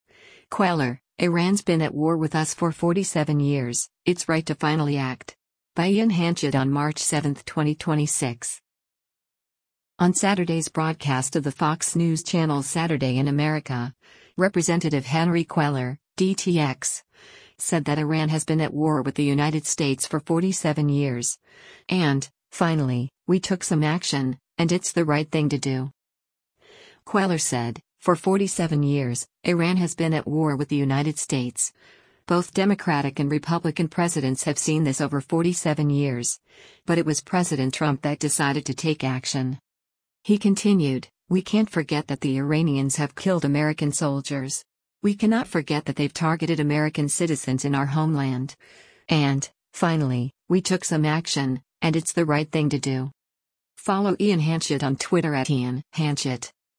On Saturday’s broadcast of the Fox News Channel’s “Saturday in America,” Rep. Henry Cuellar (D-TX) said that Iran has been at war with the United States for 47 years, “and, finally, we took some action, and it’s the right thing to do.”